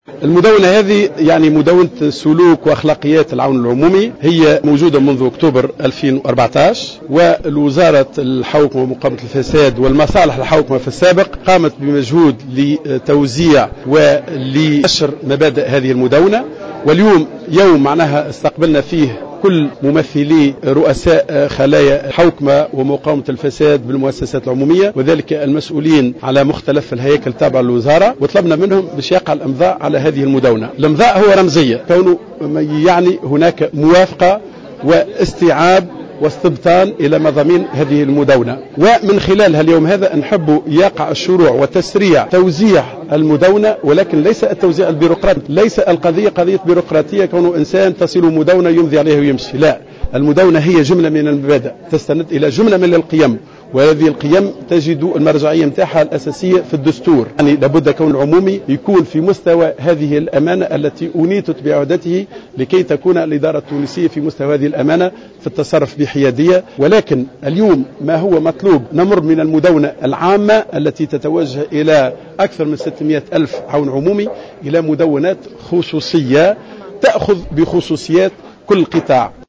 وأوضح العيادي في تصريح